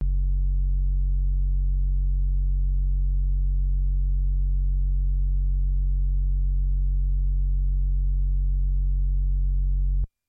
标签： MIDI网速度15 F2 MIDI音符-42 挡泥板-色度北极星 合成器 单票据 多重采样
声道立体声